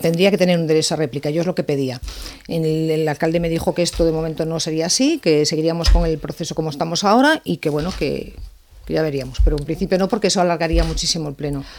En una entrevista a Ràdio Calella TV, Coronil ha criticat que la mesura s’hagi aprovat “per decret” i sense debat polític, i alerta de l’impacte que pot tenir sobre els serveis municipals.